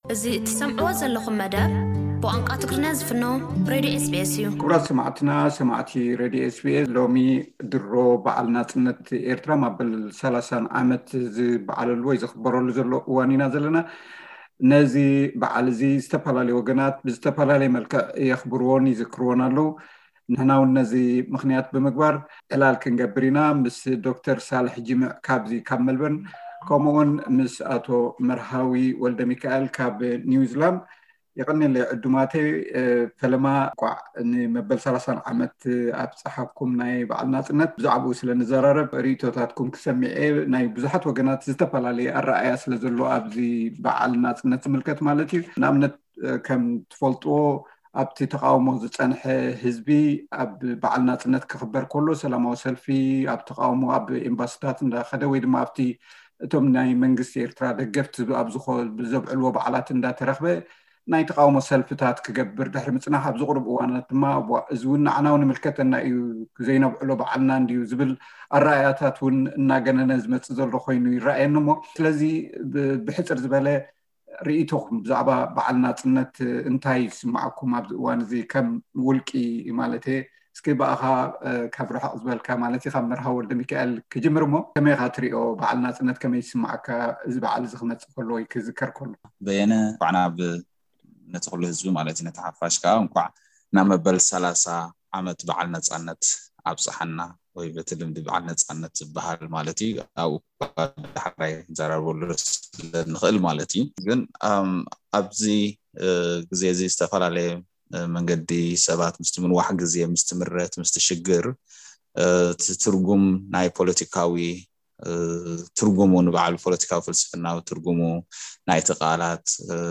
ዝተገብረ ዘተ።